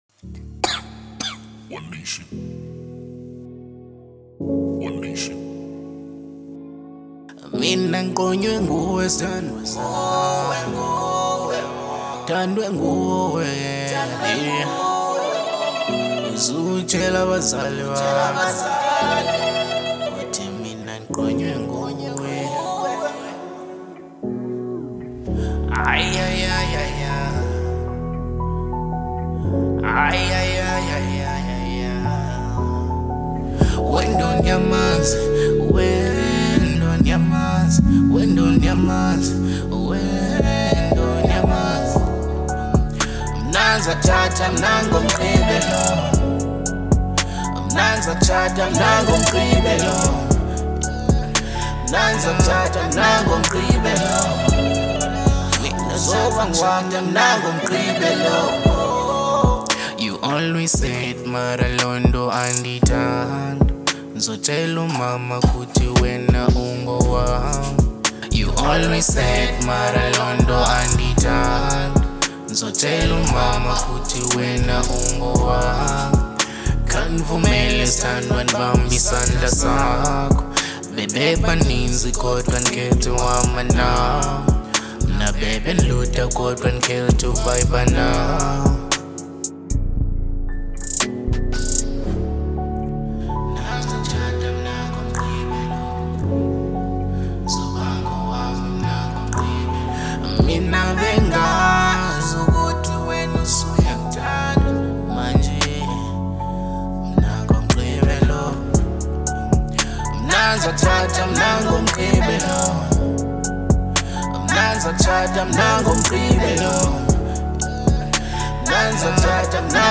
02:54 Genre : Afro Pop Size